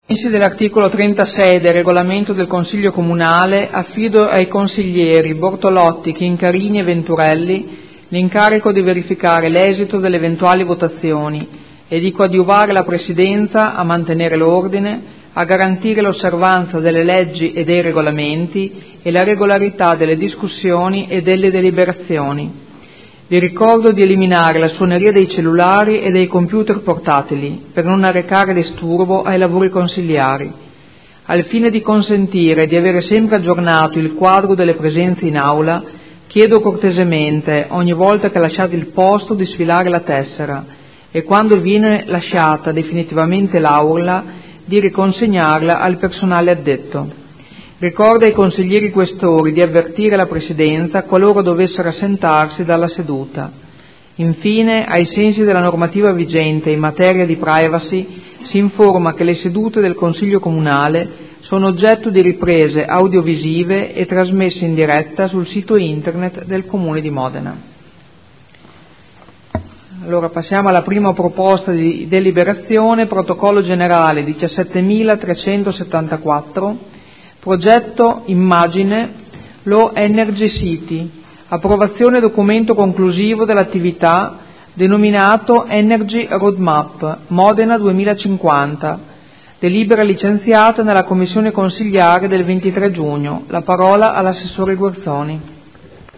Presidente — Sito Audio Consiglio Comunale
Seduta del 2 luglio. Apertura del Consiglio Comunale